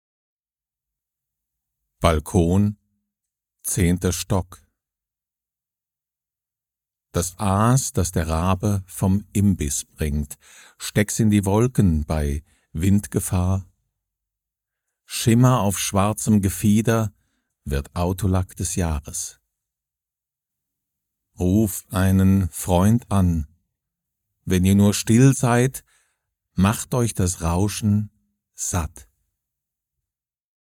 Balkon, 10. Stock Das Aas, das der Rabe vom Imbiss bringt: steck’s in die Wolken bei Windgefahr.
Tonaufnahme aus dem 10. Stock [MP3]